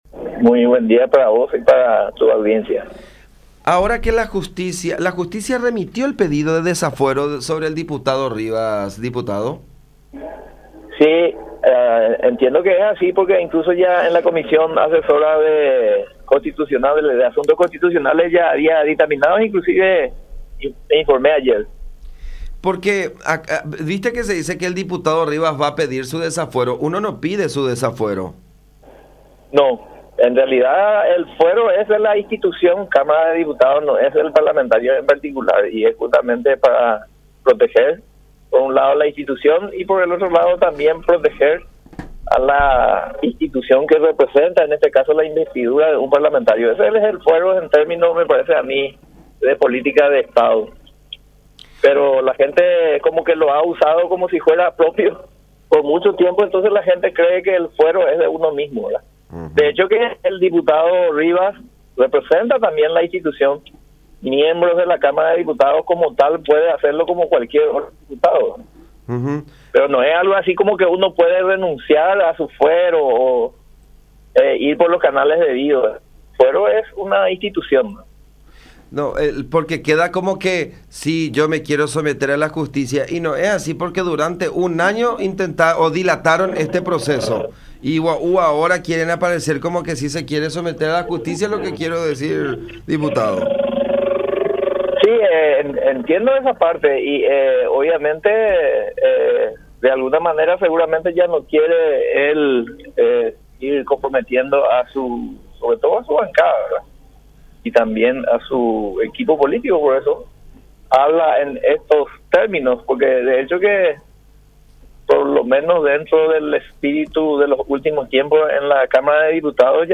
En ese orden, el diputado liberal Celso Kennedy afirmó que le “parece correcto” que sea él mismo quien solicite su desafuero, de modo a ponerse a disposición de la justicia. “No está en el orden del día, pero por moción de privilegio puede solicitar su tratamiento”, explicó en comunicación con La Unión.